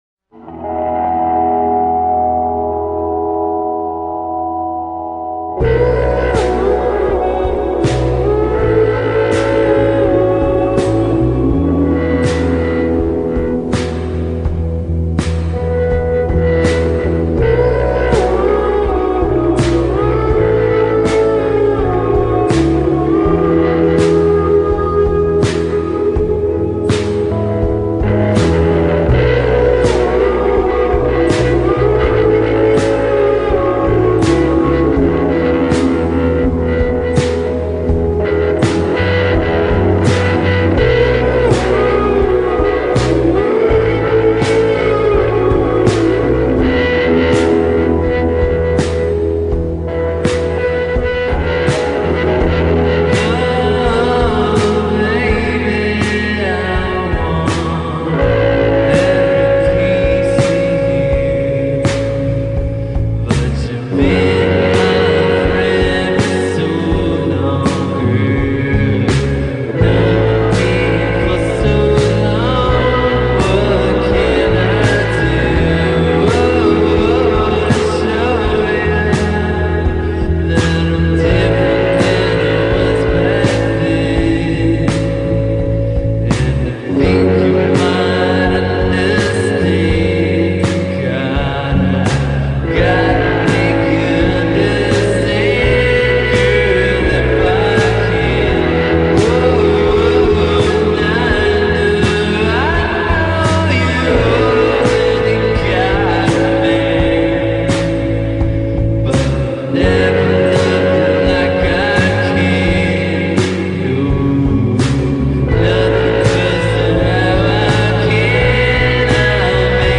This is music to melt by.